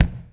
SZ KICK 23.wav